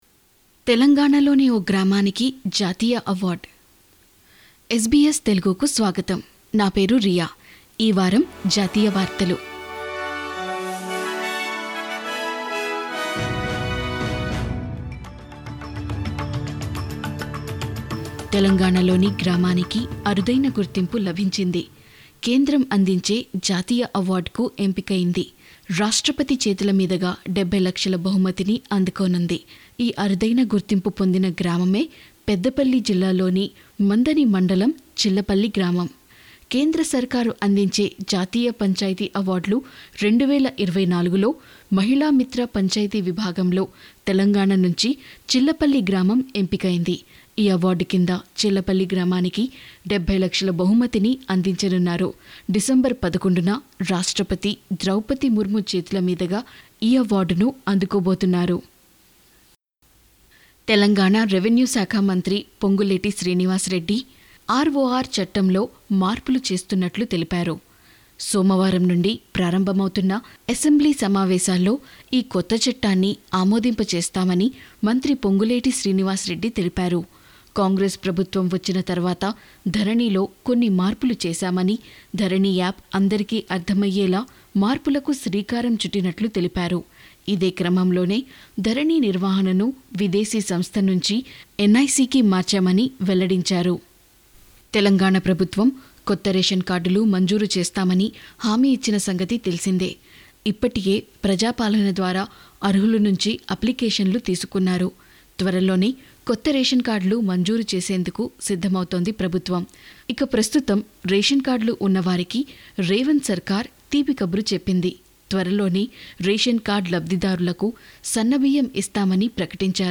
ఈ వారం జాతీయ వార్తలు..